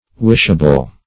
Wishable \Wish"a*ble\, a. Capable or worthy of being wished for; desirable.